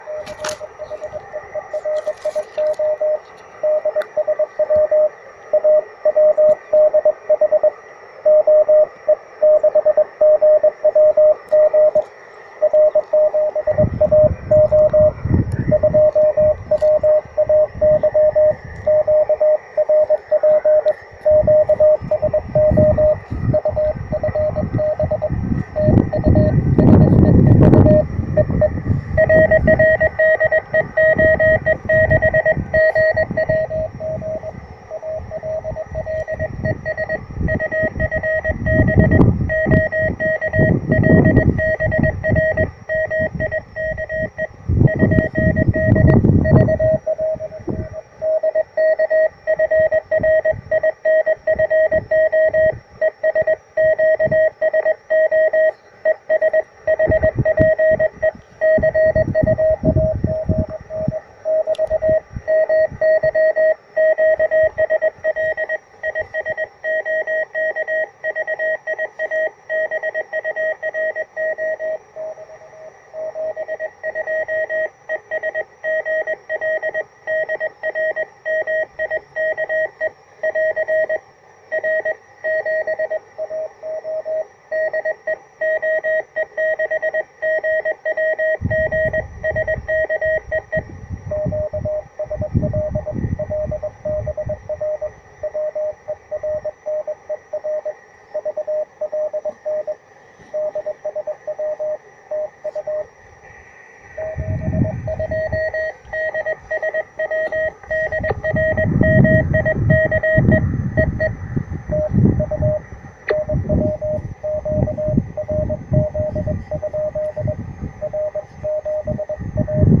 Причем это не локальный, а именно эфирный широкополосный шум с характерным призвуком.
Сигналы станций шли с большими, но ооочень медленными QSB.
Порой на 14060 стоял "пчелиный рой" и ничего разобрать нельзя было.